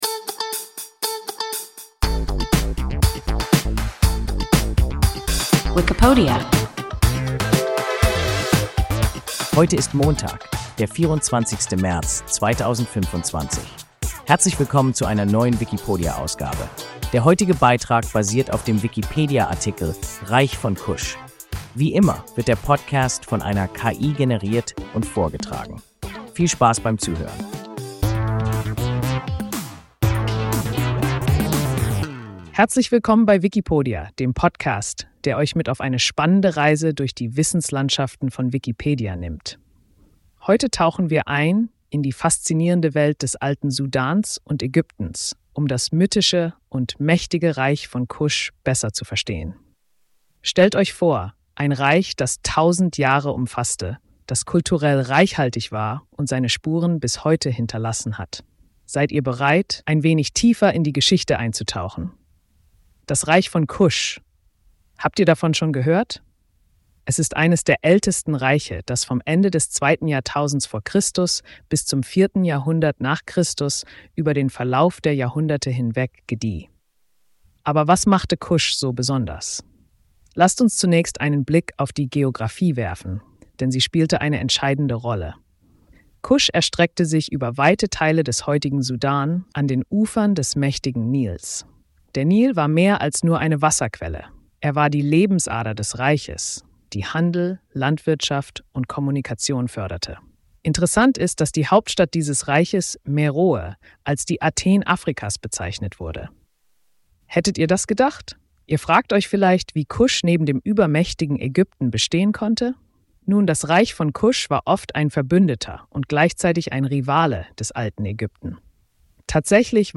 Reich von Kusch – WIKIPODIA – ein KI Podcast